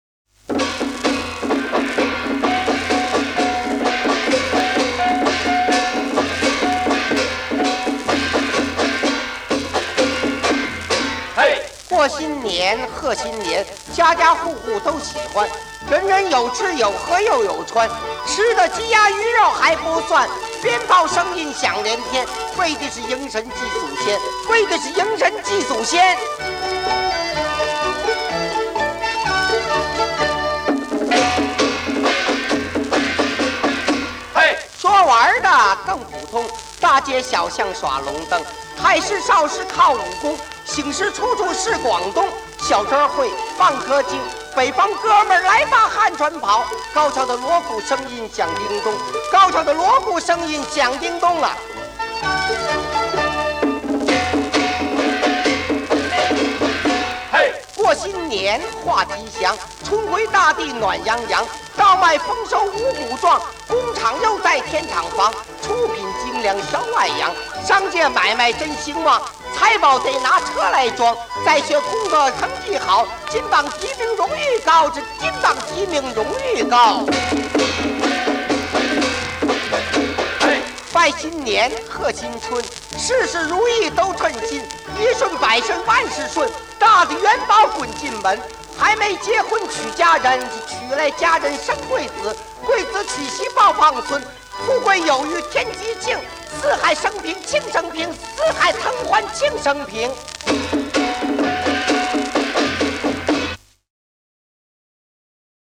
此原汁原味贺岁老歌为最初的版本，一些还带有黑胶唱片运转的声音。